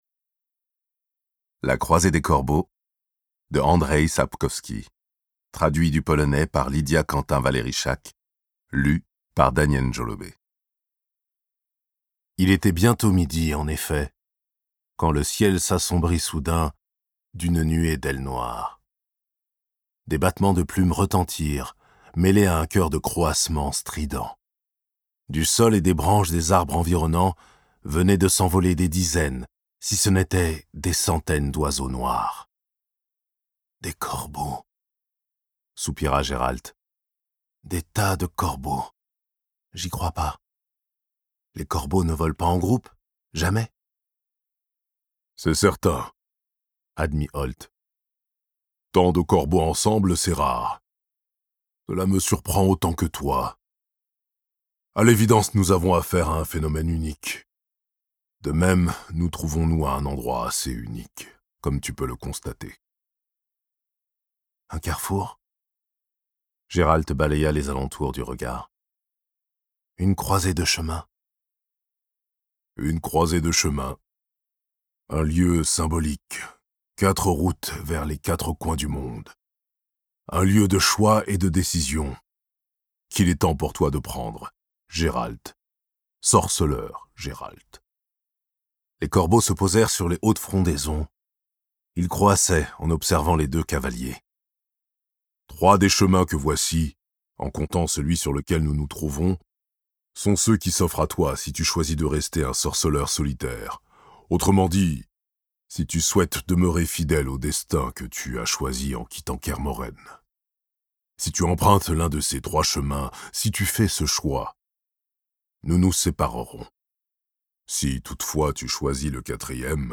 Diffusion distribution ebook et livre audio - Catalogue livres numériques
Voici comment naît une légende, et le prix à payer pour la forger. Interprétation humaine Durée : 09H40 22 , 95 € Ce livre est accessible aux handicaps Voir les informations d'accessibilité